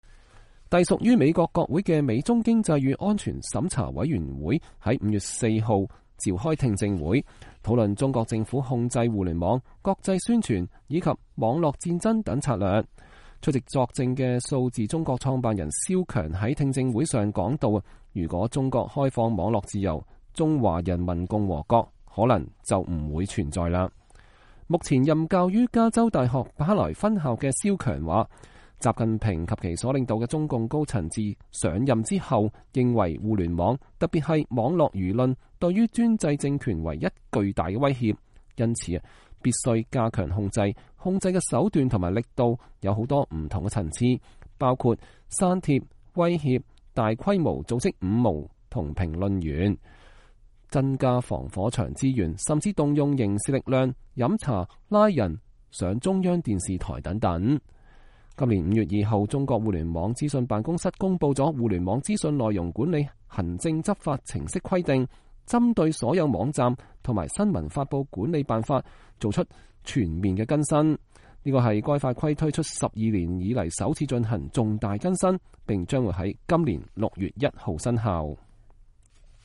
隸屬於美國國會的美中經濟與安全審查委員會(US-China Economic and Security Review Commission, USCC)5月4日召開聽證會，討論中國政府控制互聯網、國際宣傳以及網絡戰爭策略。